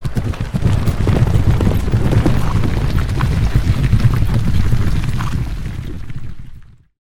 sound-of-walking-horses